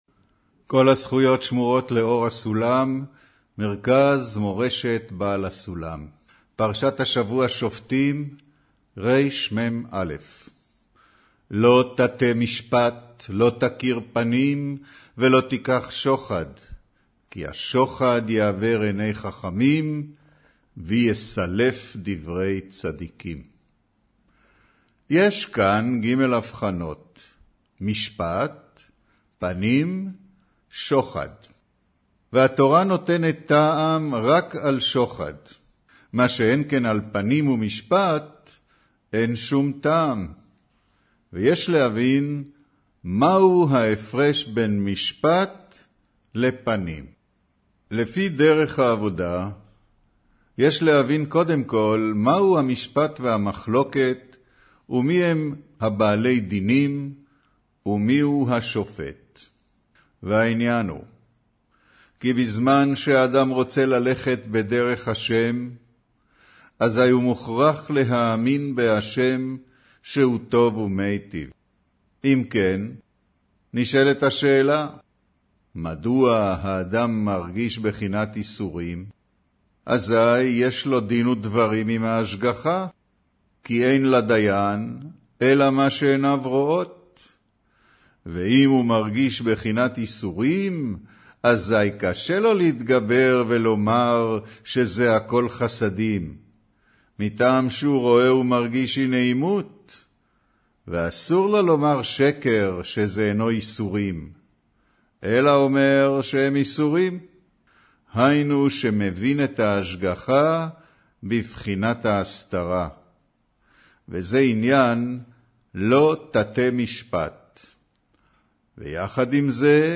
אודיו - קריינות פרשת שופטים, מאמר לא תטה משפט